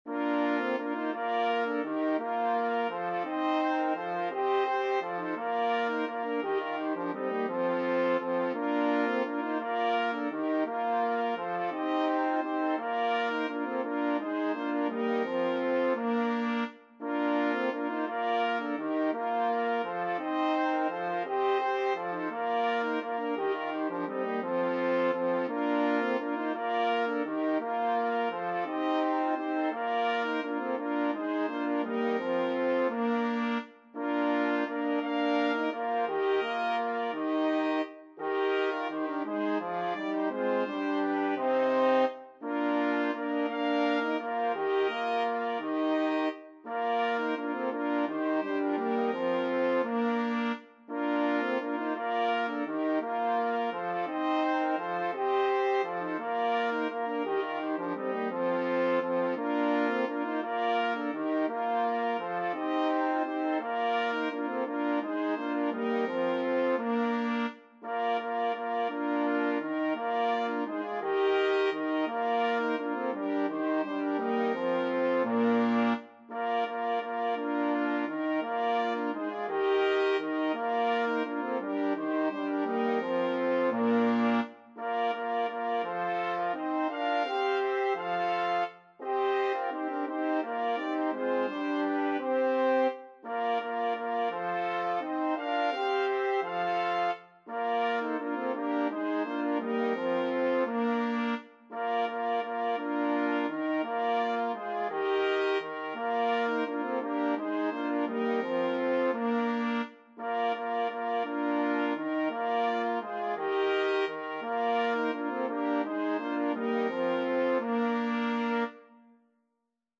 Free Sheet music for Brass Quartet
Trumpet 1Trumpet 2French HornTrombone
3/2 (View more 3/2 Music)
Bb major (Sounding Pitch) (View more Bb major Music for Brass Quartet )
Brass Quartet  (View more Intermediate Brass Quartet Music)
Classical (View more Classical Brass Quartet Music)
danserye_2_bergerette_BRQT.mp3